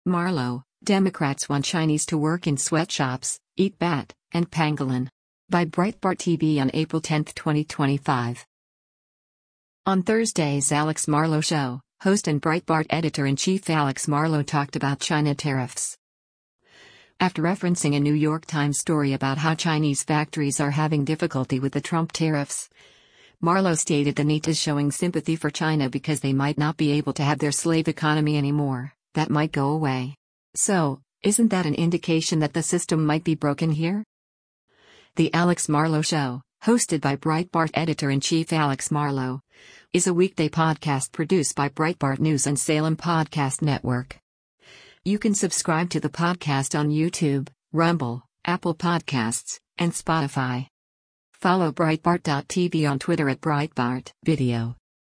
On Thursday’s “Alex Marlow Show,” host and Breitbart Editor-in-Chief Alex Marlow talked about China tariffs.